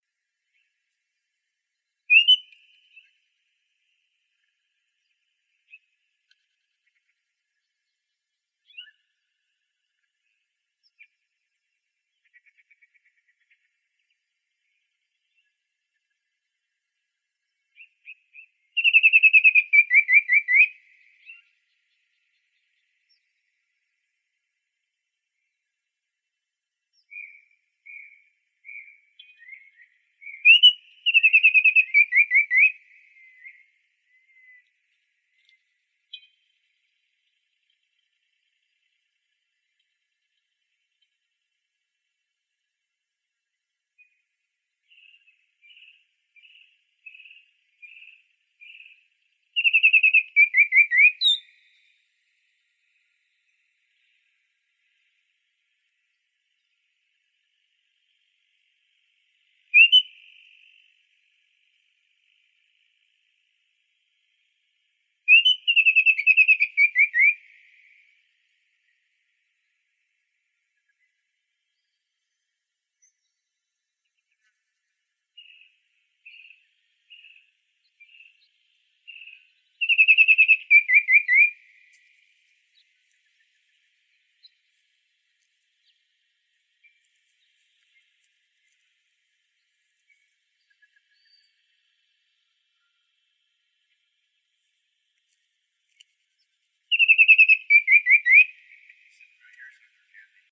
baltimoreoriole.wav